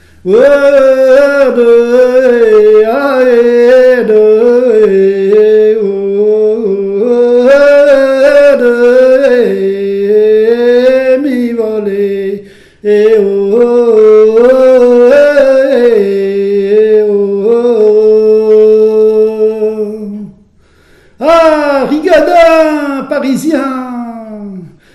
Dariolage
Appels de labour, tiaulements, dariolage, teurlodage, pibolage
couplets vocalisés
chant pour mener les boeufs constitué d'onomatopées, interjections et de noms de boeufs